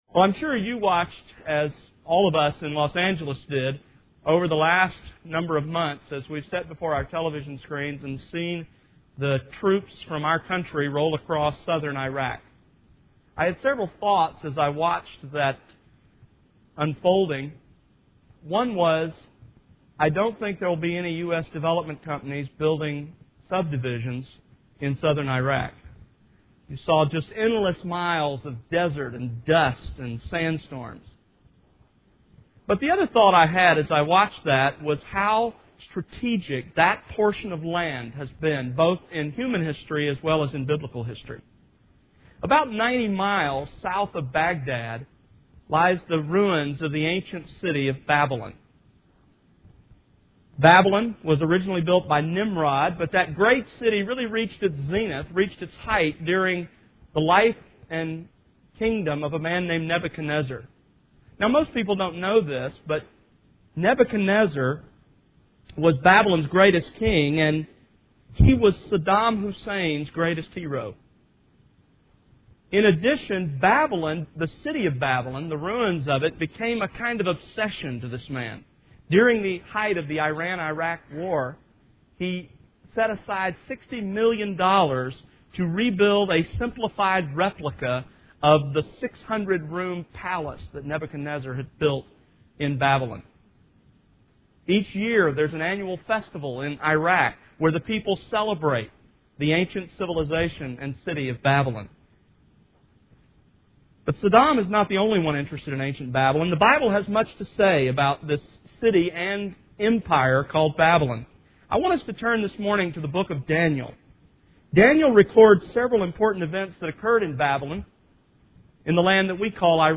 Sermons That Exalt Christ